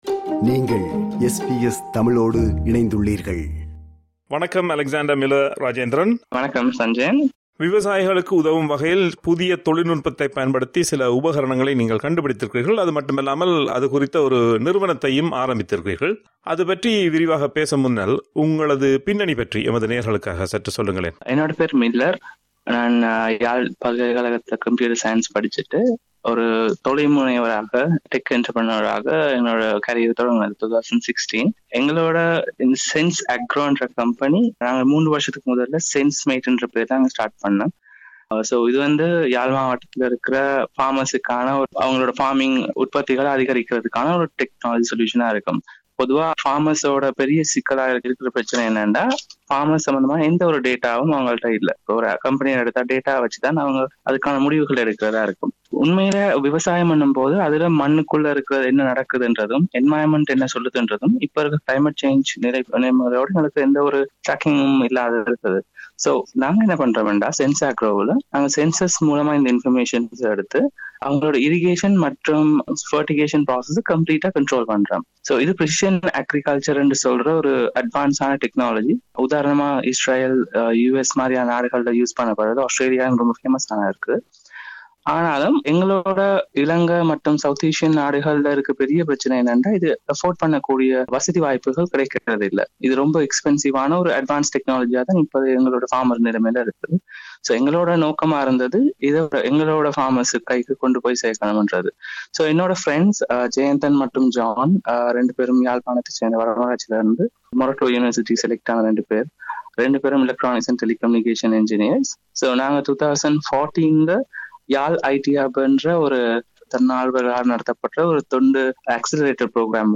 ஆகிய இருவரை நேர்காண்கிறார்